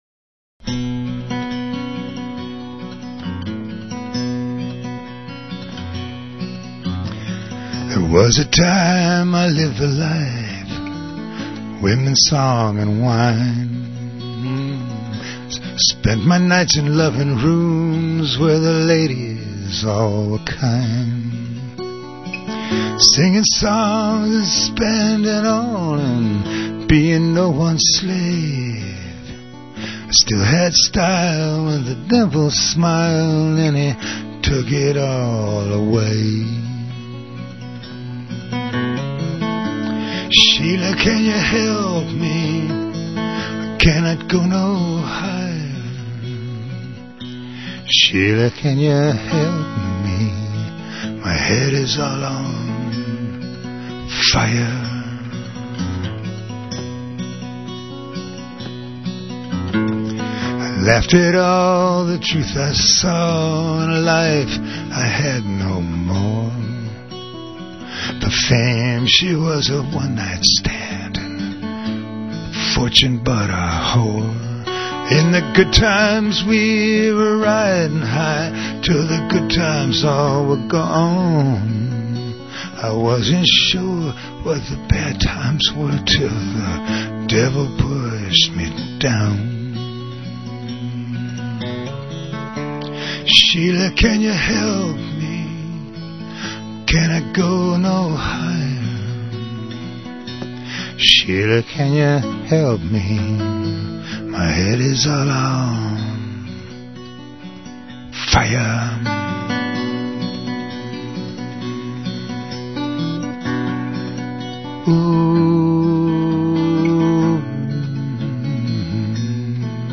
live songs (from radio)
mono